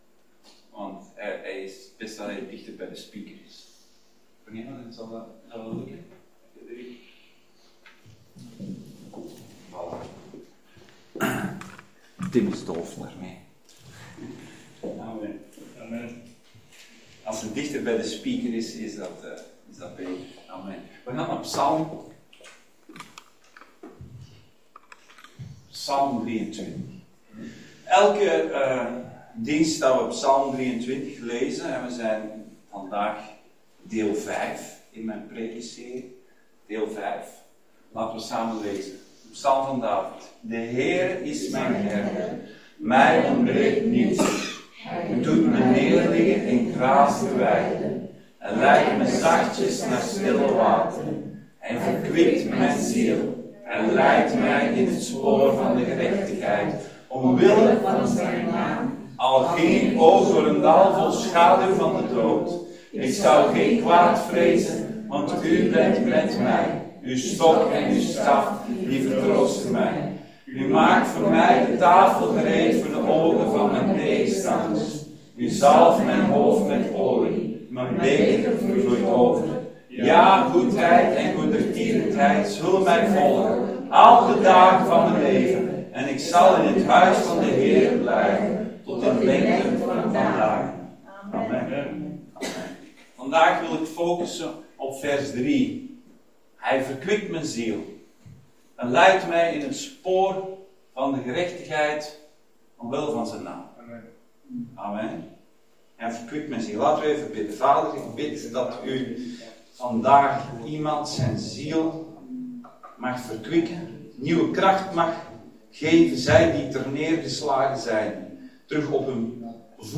Dienstsoort: Zondag Dienst